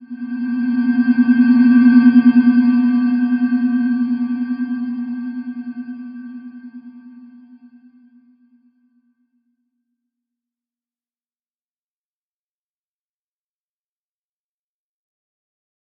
Slow-Distant-Chime-B3-f.wav